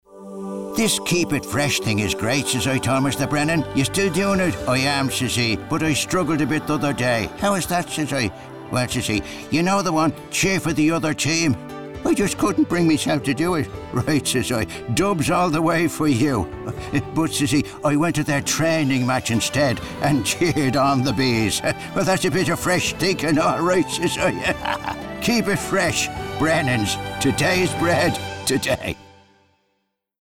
Radio: